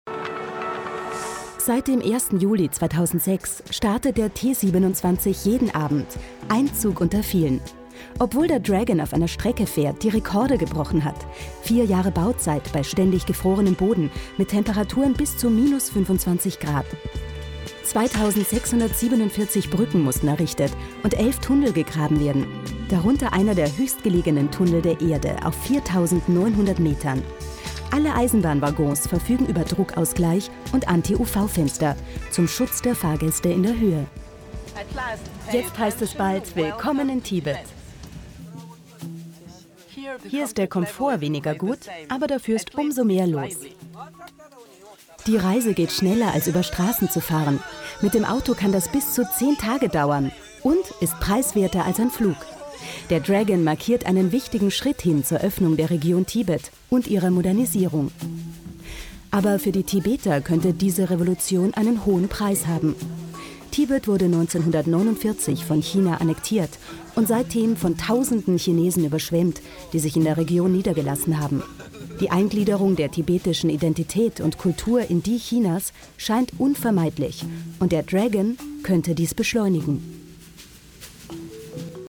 sprecherin dokumentationen . voice over . off stimme . doku sprecher
TV Doku/Off Stimme Voice Over /All Aboard/Servus TV/mp3
off_voiceover_servustv.mp3